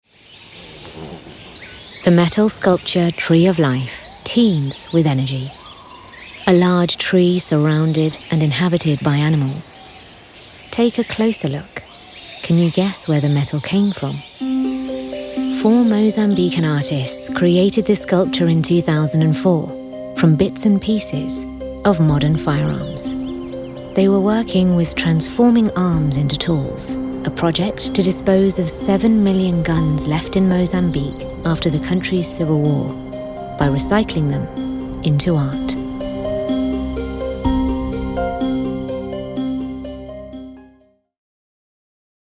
English (British)
Natural, Playful, Urban, Friendly, Warm
Audio guide